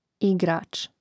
ìgrāč igrač